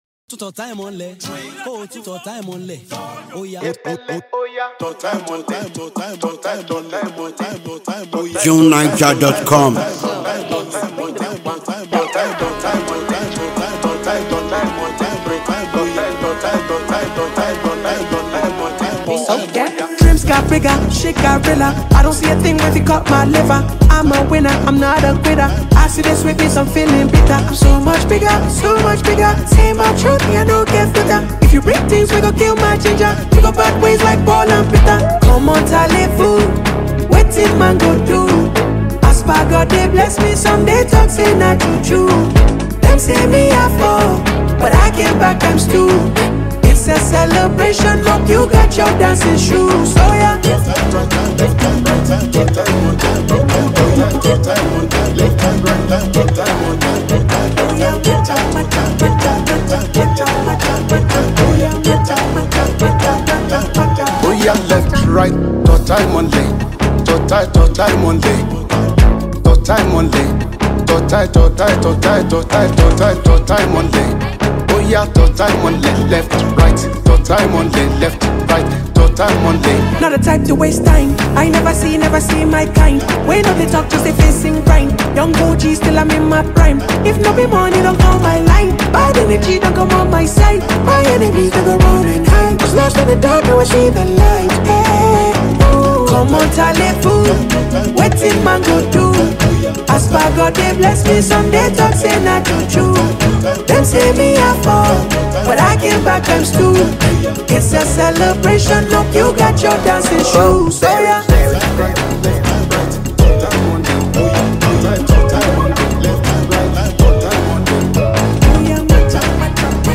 captivating and fiery popular song